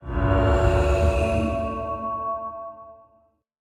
Minecraft Version Minecraft Version latest Latest Release | Latest Snapshot latest / assets / minecraft / sounds / block / beacon / power1.ogg Compare With Compare With Latest Release | Latest Snapshot
power1.ogg